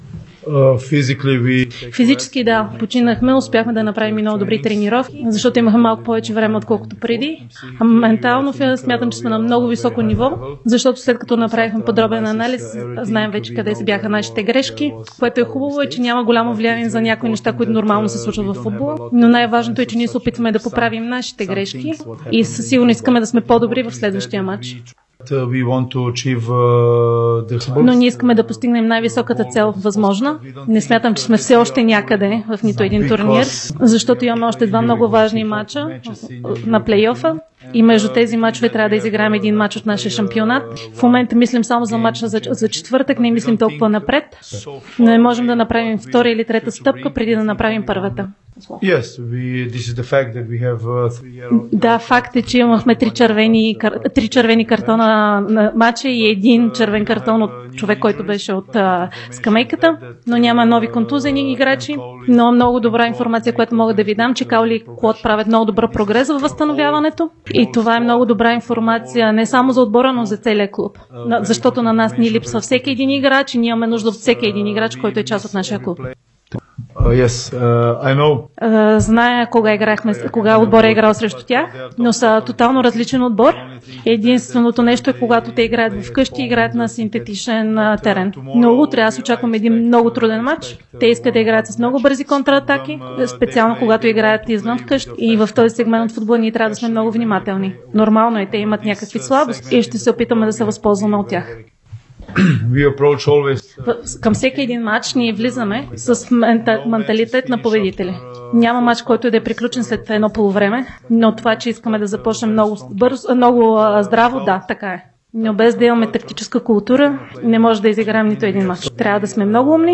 Старши треньорът на Лудогорец Анте Шимунджа говори пред журналистите преди мача срещу Жалгирис от Лига Европа.